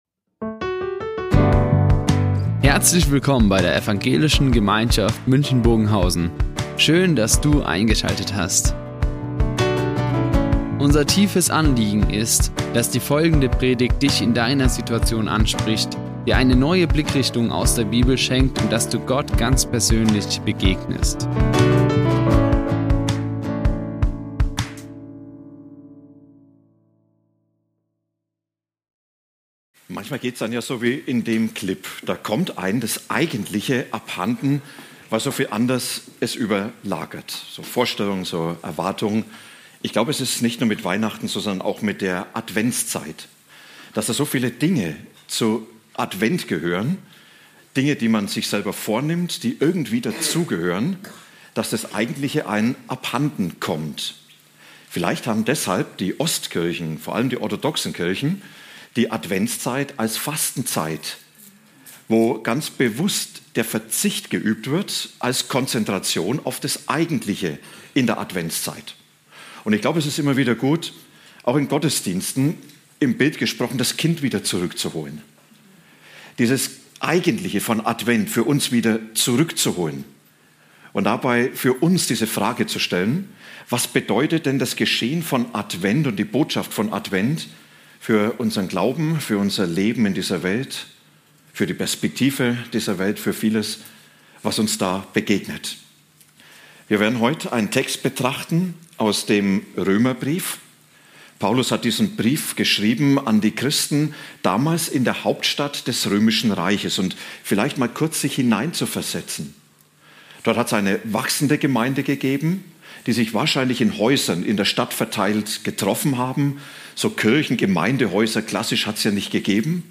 Nicht weniger als Freude | Predigt Römer 15, 12-13 ~ Ev.
12-13 zum Thema "Nicht weniger als Freude" Die Aufzeichnung erfolgte im Rahmen eines Livestreams.